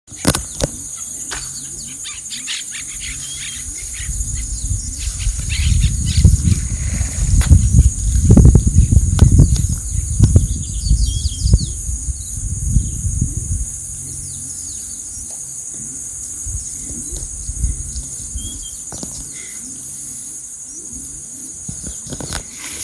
Burrito Amarillo (Laterallus flaviventer)
Nombre en inglés: Yellow-breasted Crake
Localidad o área protegida: Concordia
Condición: Silvestre
Certeza: Vocalización Grabada